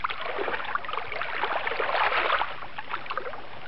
FX - WATER.wav